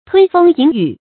吞風飲雨 注音： ㄊㄨㄣ ㄈㄥ ㄧㄣˇ ㄧㄩˇ 讀音讀法： 意思解釋： 猶言餐風宿露。形容四處奔波，生活艱辛。